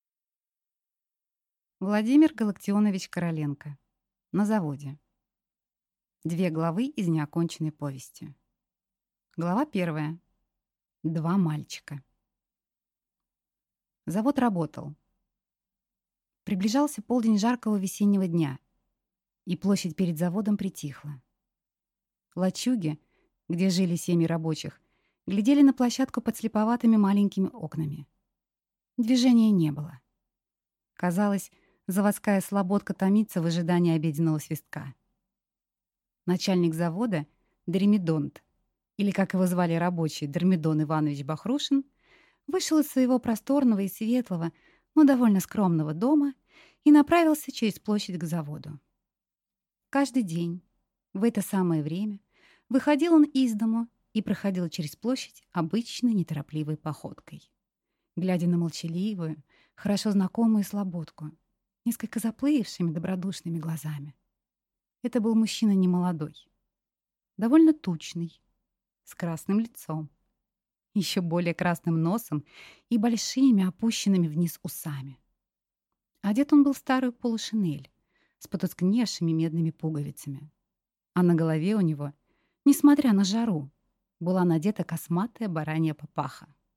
Аудиокнига На заводе | Библиотека аудиокниг